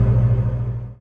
bet-locomotive-deactivate.ogg